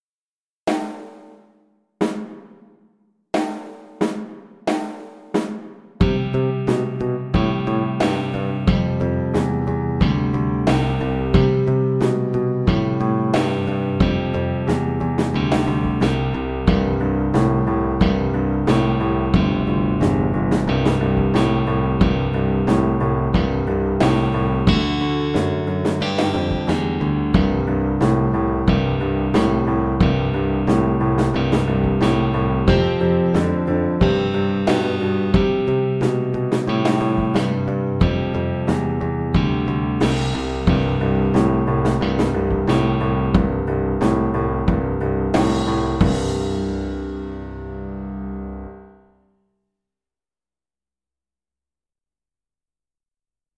A beginners piece with a rock-like descending bass line.
4/4 (View more 4/4 Music)
March-like = 90
Arrangement for Viola and Piano
Pop (View more Pop Viola Music)